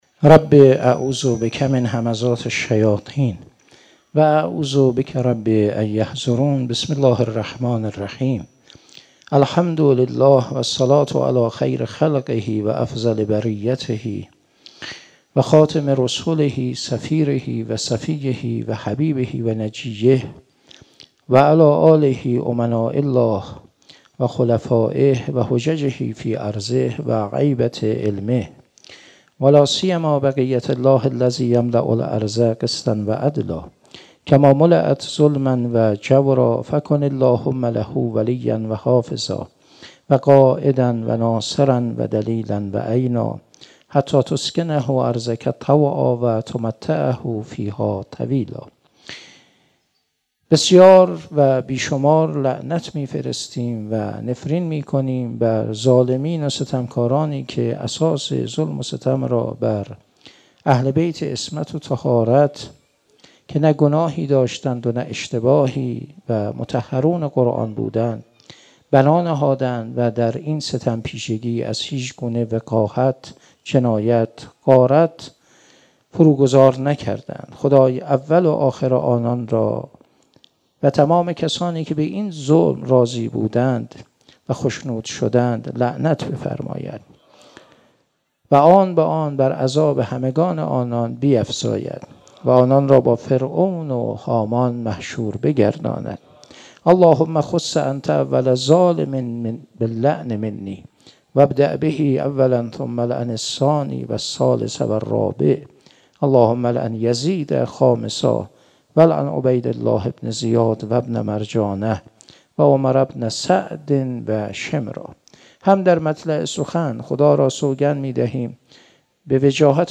شب سوم محرم 96 - هیئت عشاق الحسین - سخنرانی